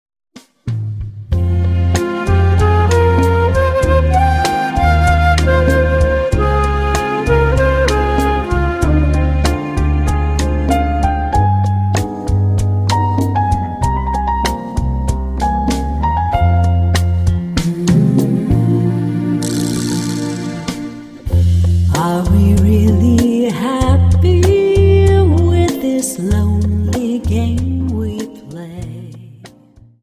Dance: Rumba 24